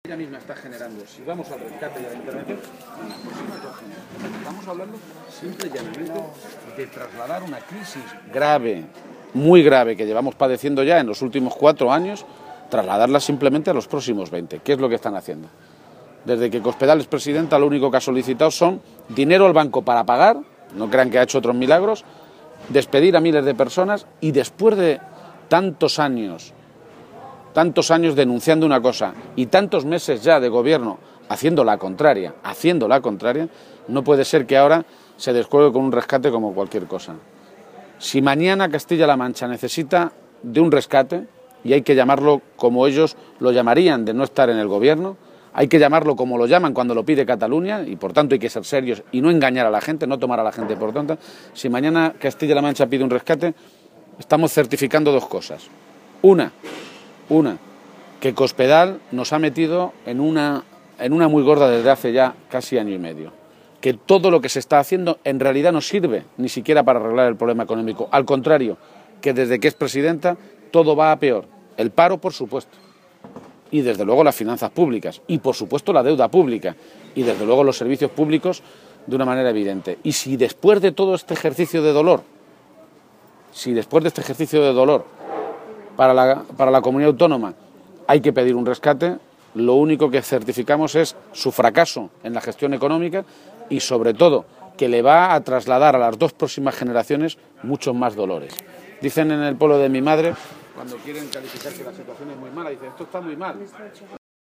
García-Page adelantaba esta noticia a preguntas de los medios de comunicación durante su visita a la localidad conquense de Tarancón con motivo de sus fiestas patronales en honor de la Virgen de Riánsares.